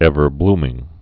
(ĕvər-blmĭng)